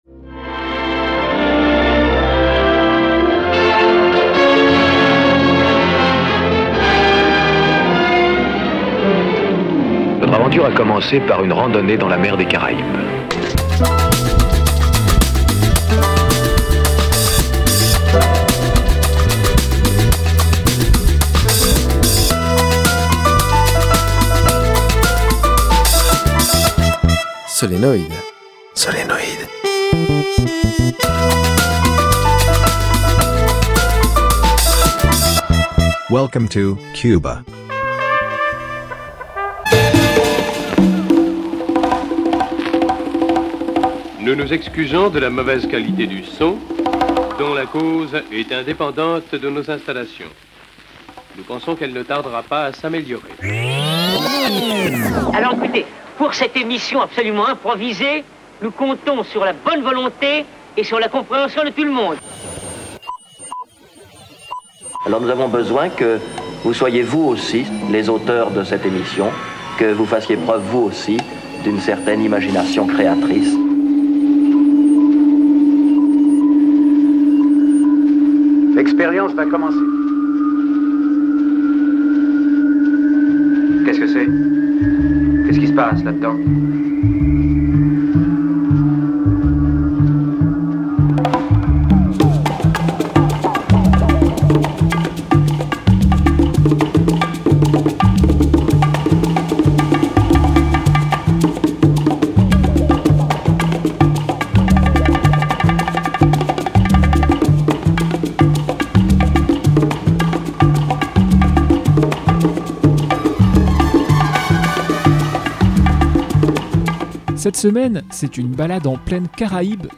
Plongez dans la magie mélodico-rythmique de CUBA !